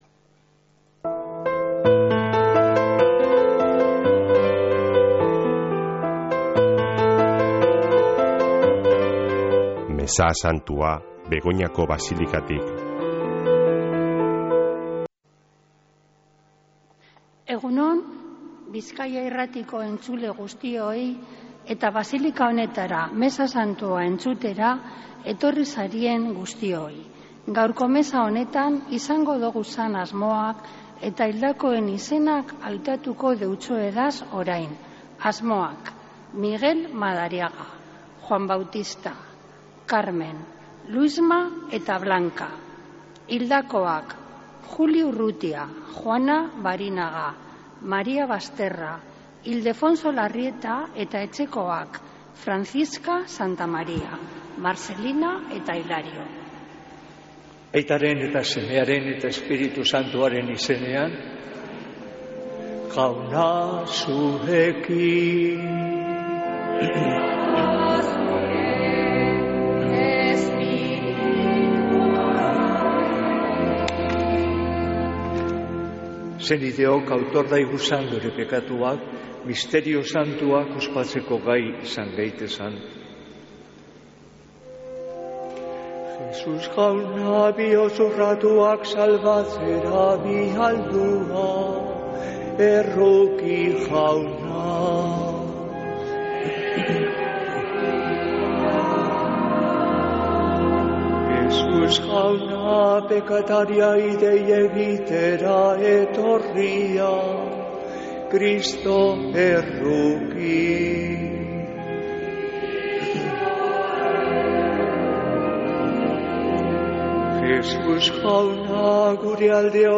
Mezea Begoñako Basilikatik